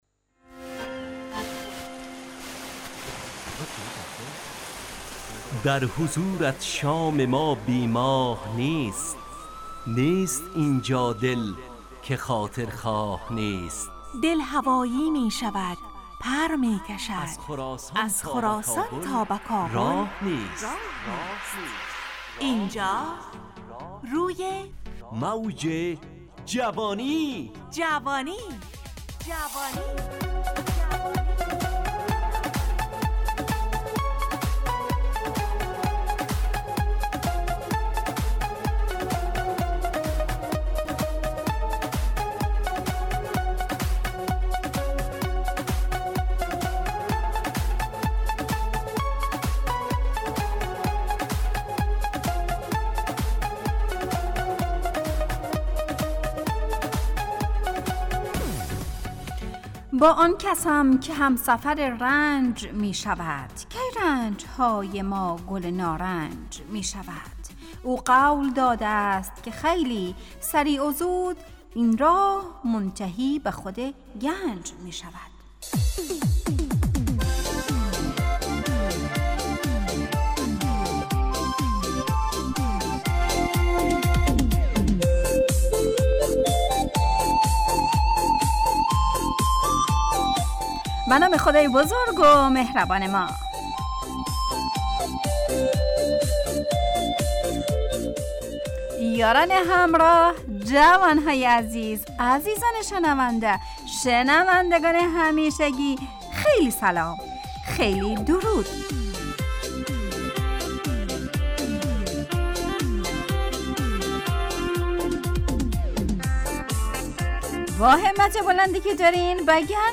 همراه با ترانه و موسیقی مدت برنامه 55 دقیقه . بحث محوری این هفته (رنج و گنج) تهیه کننده